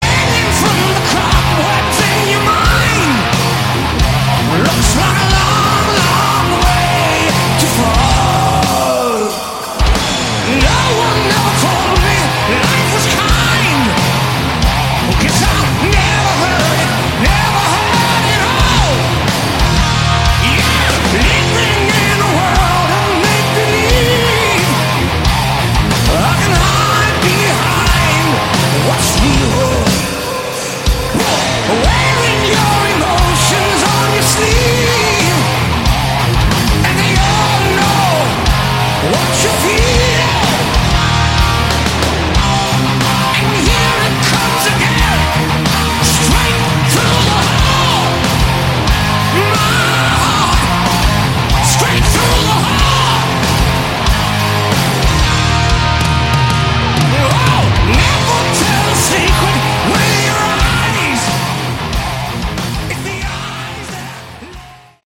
Category: Melodic Metal
live